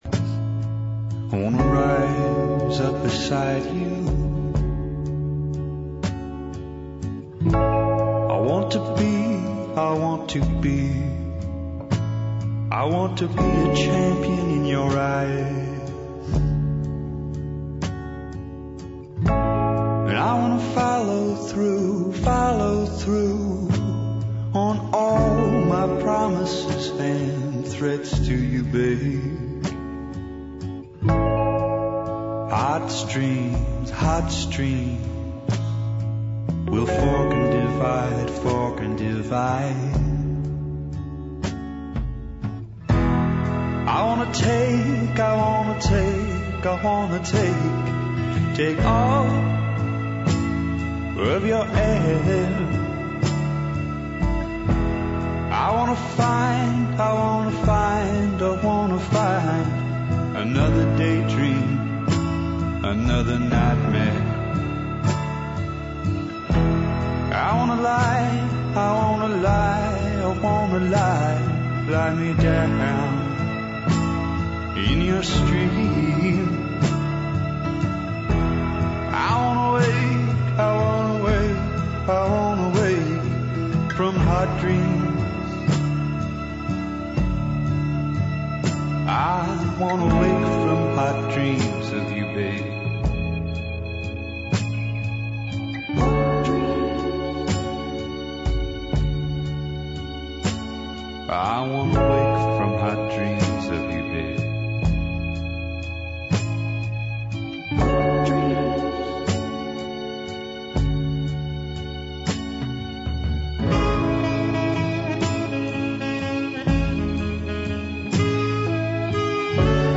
У првом делу емисије, представићемо свих 16 хитова, колико је укупно објављено. У другом делу ћемо се бавити домаћом рок сценом 80-тих, по многима, златном добу домаће поп и рок музике. У последњем сату ћемо слушати концертне снимке легендарних рок бендова.